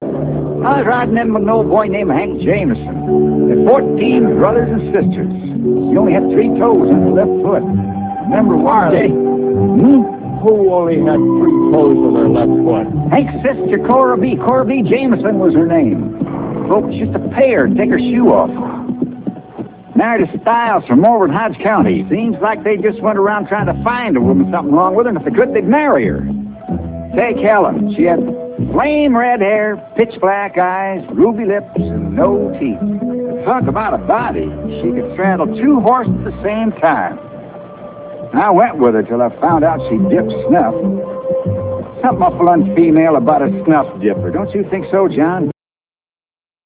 3toes.real audio-50kbHarley talking abot a womans three toes!